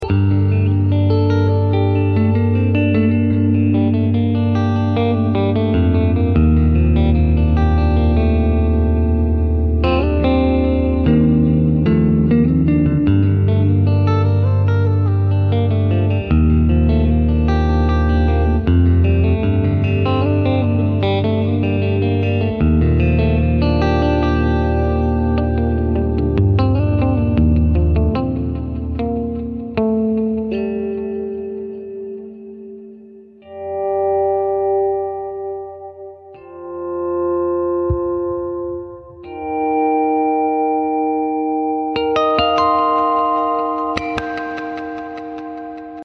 Virtual Guitar